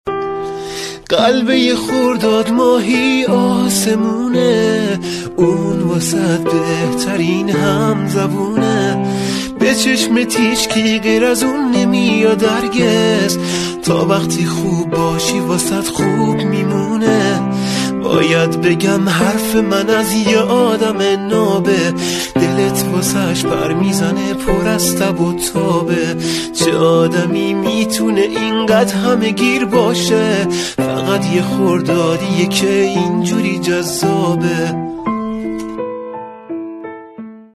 با صدای مرد با گیتار